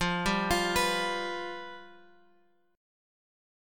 Fsus2b5 chord {x x 3 4 6 3} chord